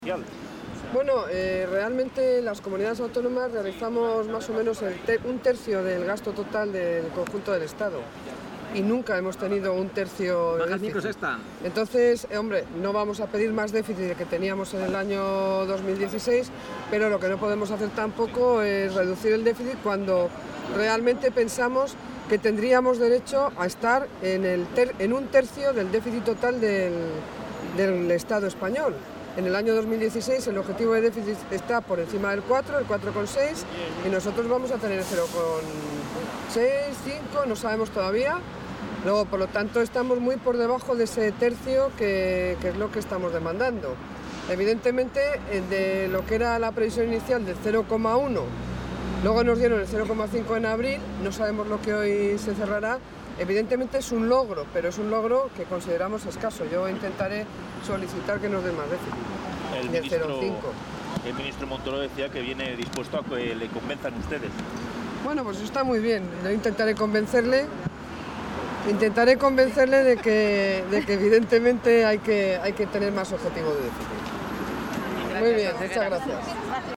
Declaraciones de la consejera de Economía y Hacienda antes del Consejo de Política Fiscal y Financiera
Audio consejera de Economía y Hacienda.